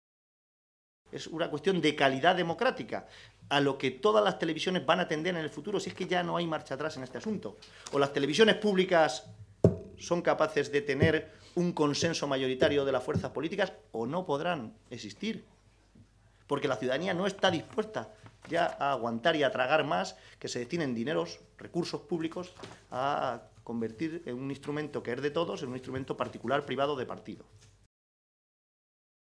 Caballero se pronunciaba de esta manera esta mañana, en Toledo, en una comparecencia ante los medios de comunicación en la que explicaba que esta iniciativa tiene como objetivo “acabar con la manipulación y la tergiversación vergonzosa que sufren la televisión de Castilla-La Mancha desde que está en manos de Cospedal y Villa y que solo es equiparable a la de la televisión de Corea del Norte”.